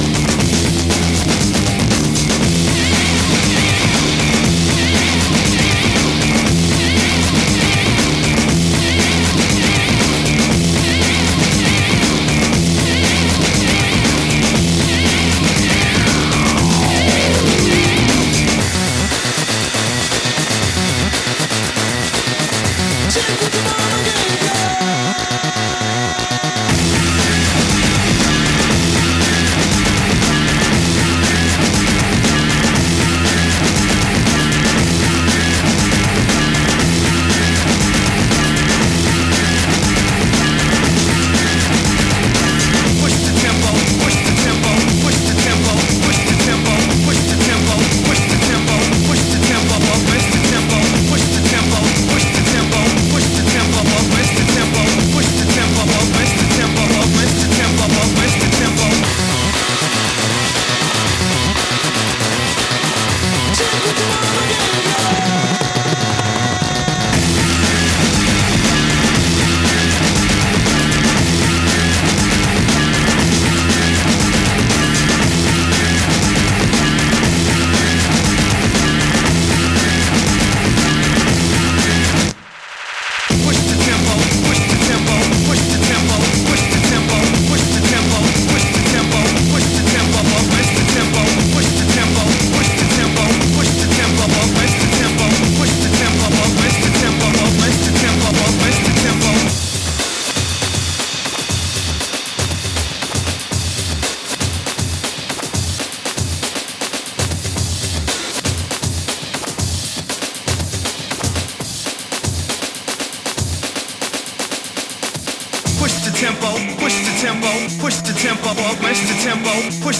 Styl: House, Techno, Breaks/Breakbeat